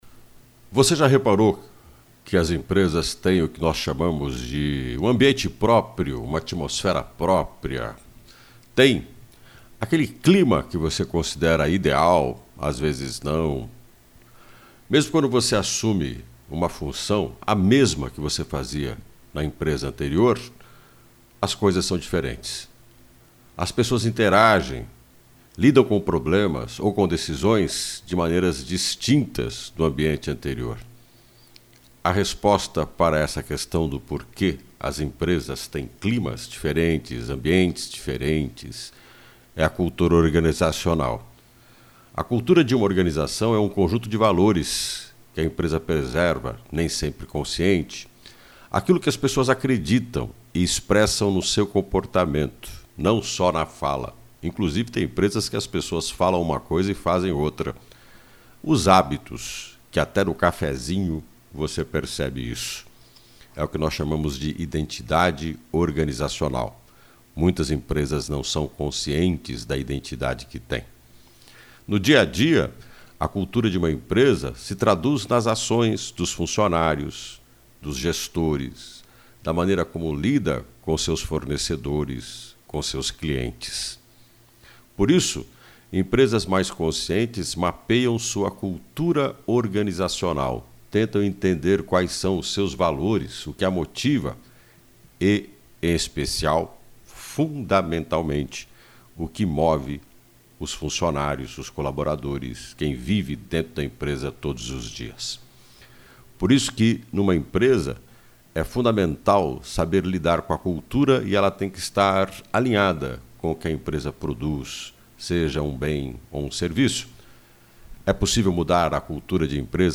O comentário